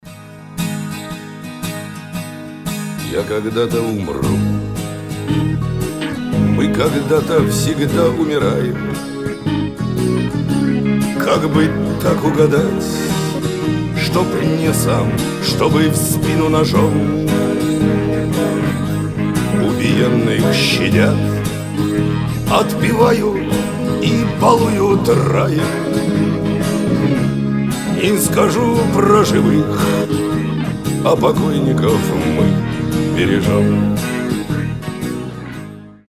гитара
70-е
барды